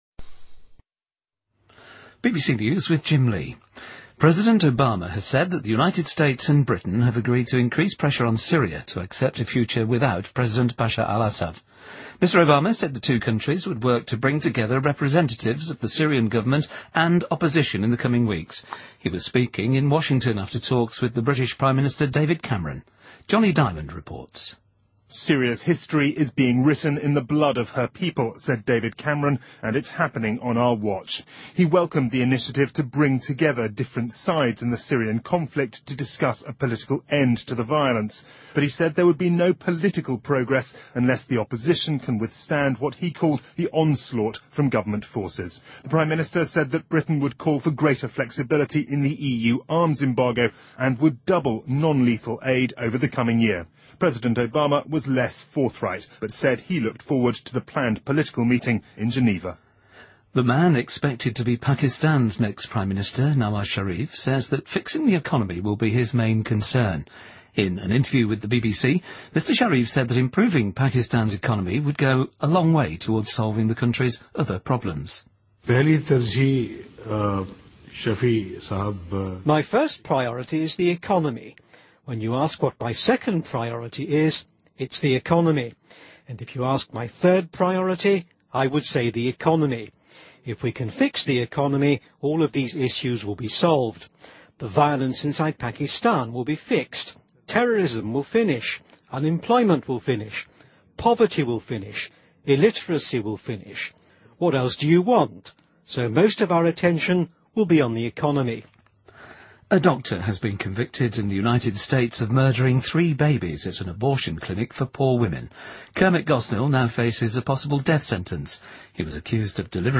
BBC news,2013-05-14